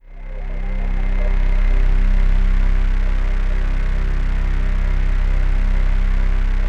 ATMOPAD31 -LR.wav